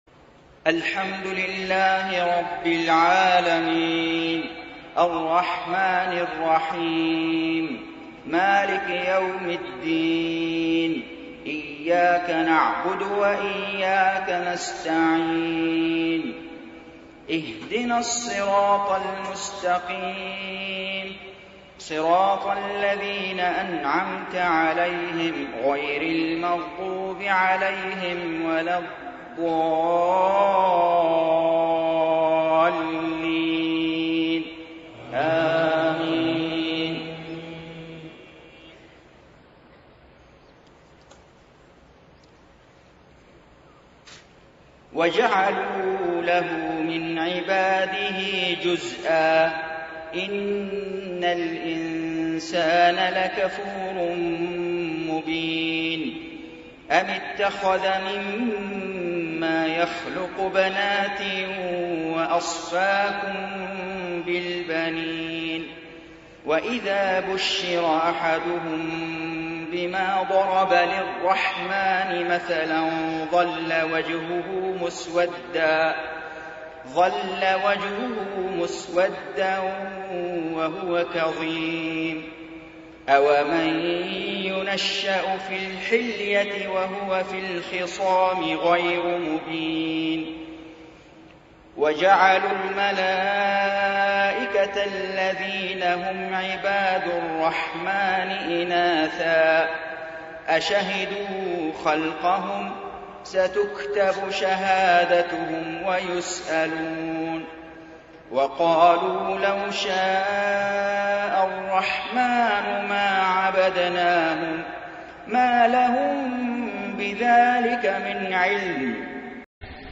صلاة العشاء 27 شوال 1432هـ من سورة الزخرف 15-30 > 1432 🕋 > الفروض - تلاوات الحرمين